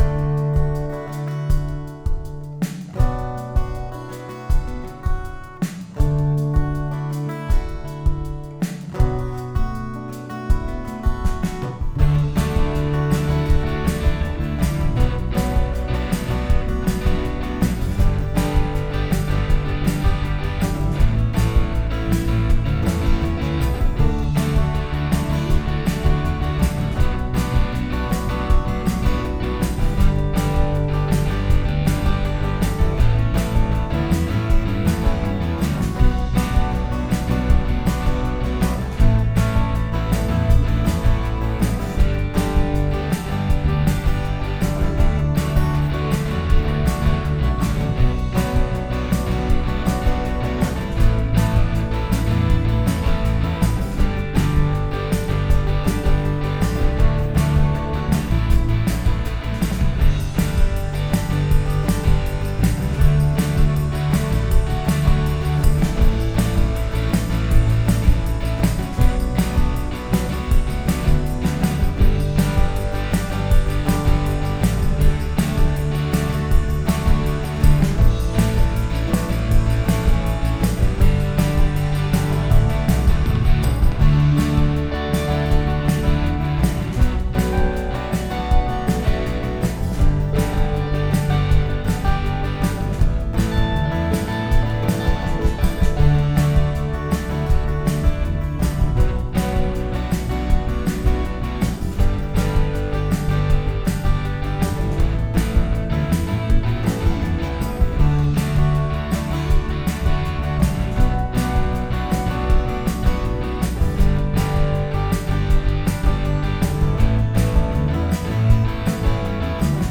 Da i chjassi di a scola à l’Università_voce zitelli remixé.m4a (1.95 Mo)